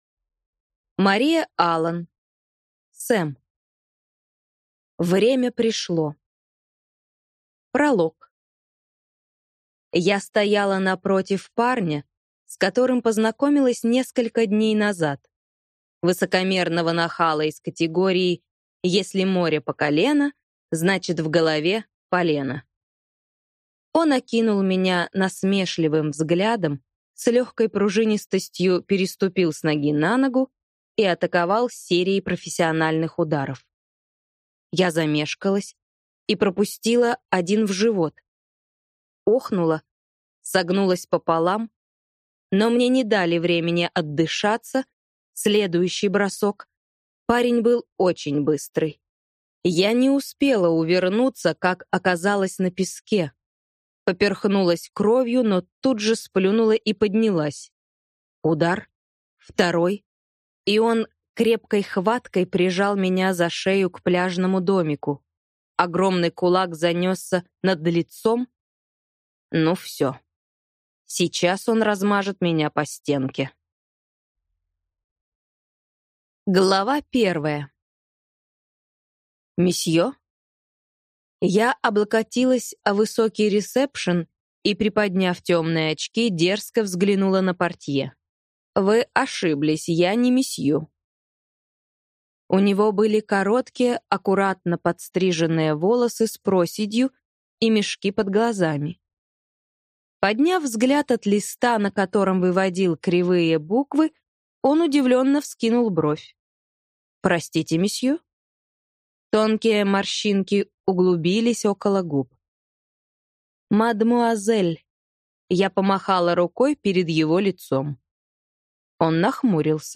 Аудиокнига Сэм | Библиотека аудиокниг